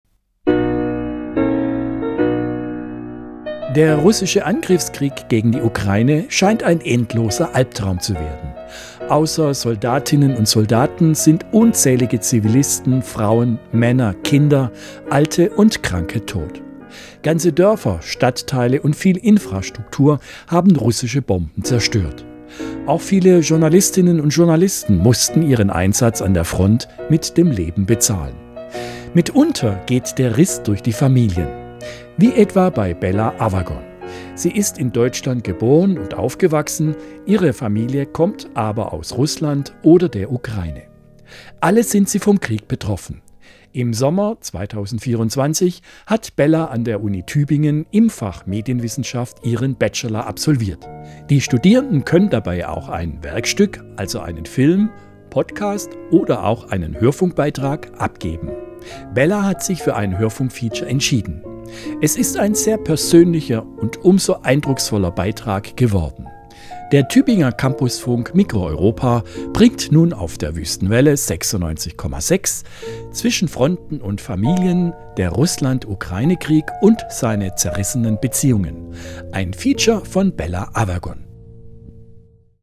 In diesem Hörfunkfeature wird es sehr ernst und emotional. Es geht um Beziehungen und Familienleben während des russischen Angriffkrieg in der Ukraine